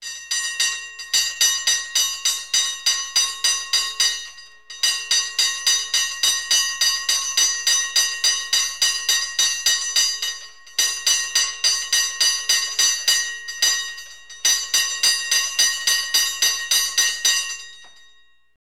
Hard Bell Sound Effect Free Download
Hard Bell